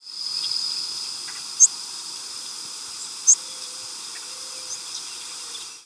Northern Waterthrush diurnal flight calls
Bird in flight with Great-tailed Grackle and Mourning Dove calling in the background.